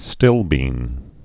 (stĭlbēn)